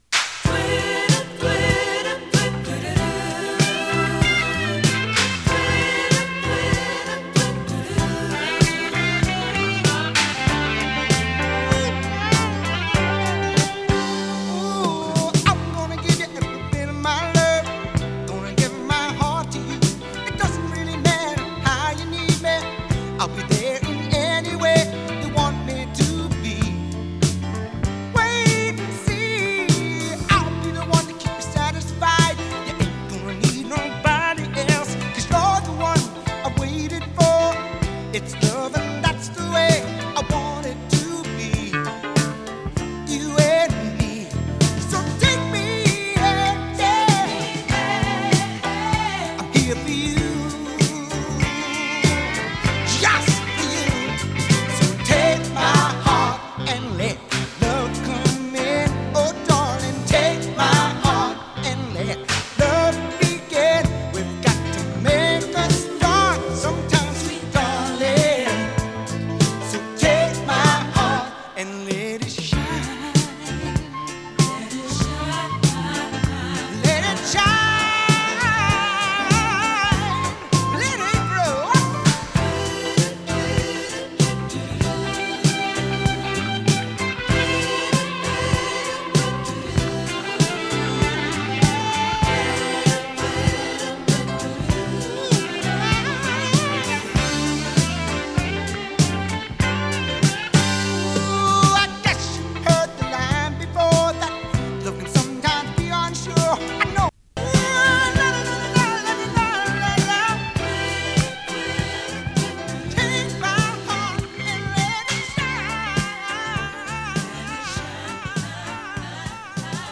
ブル－アイドソウル
＊音の薄い部分で時折パチ・ノイズ。